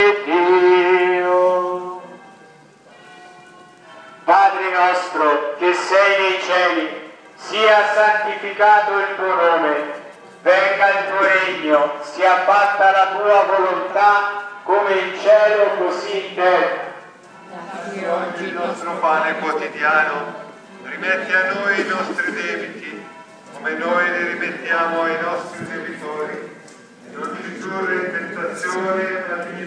Procession barga